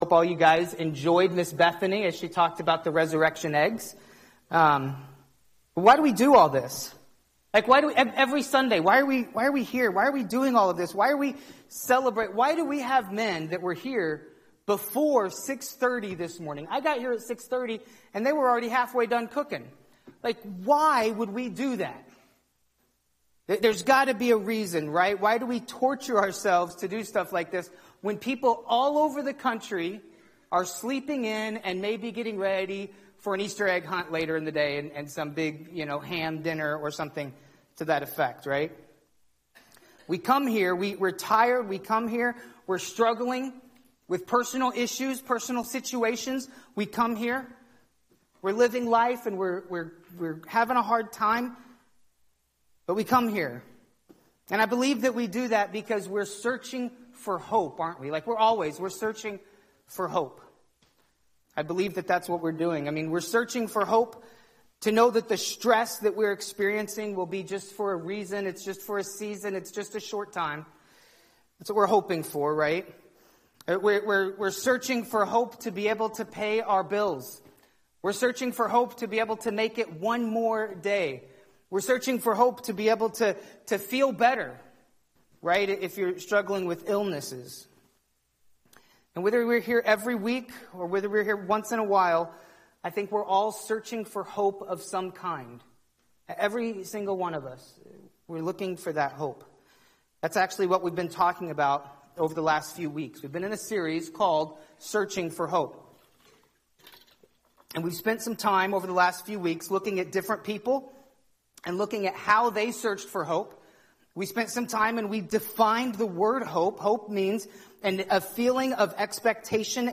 Finding True Hope (Easter morning)